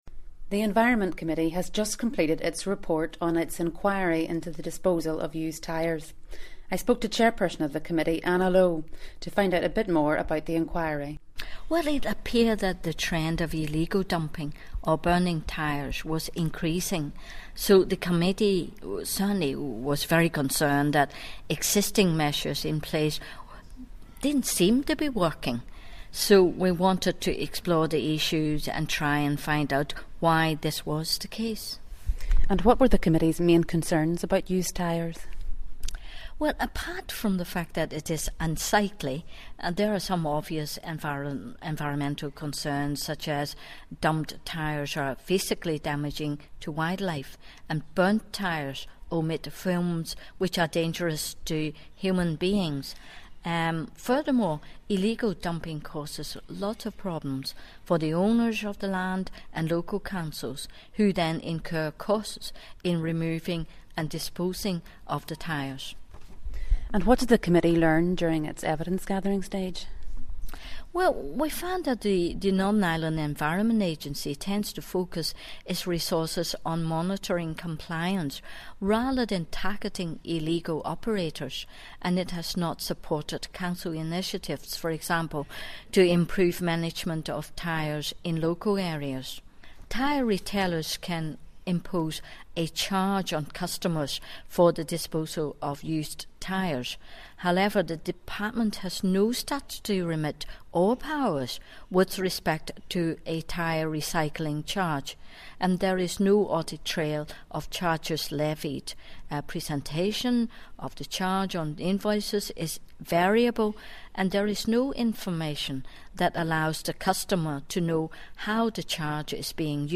The Committee for the Environment has published its Final Report on its Inquiry into Used Tyre Disposal. Listen to Committee Chairperson, Anna Lo, outline the Committee’s findings.